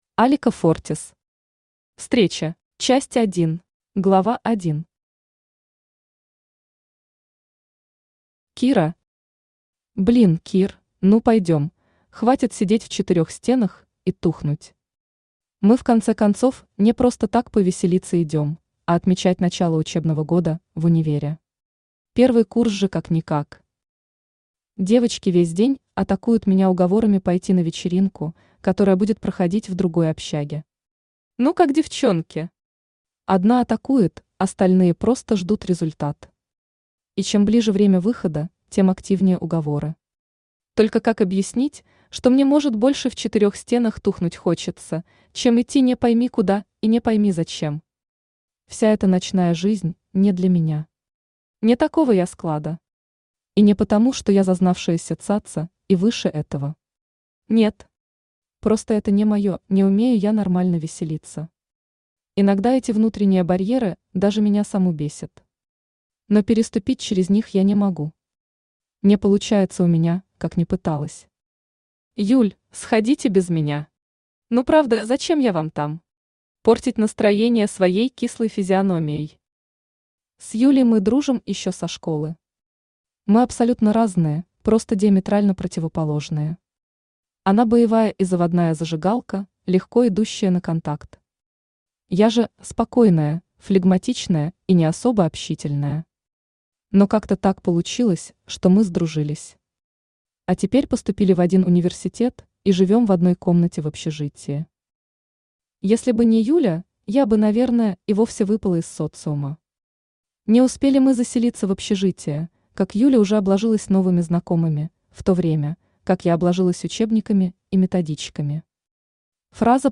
Аудиокнига Встреча | Библиотека аудиокниг
Aудиокнига Встреча Автор Алика Фортис Читает аудиокнигу Авточтец ЛитРес.